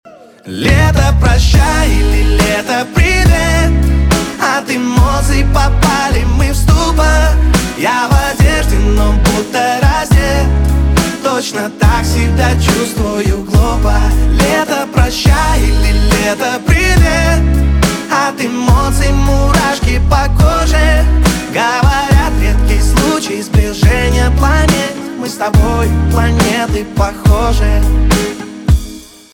поп
барабаны , гитара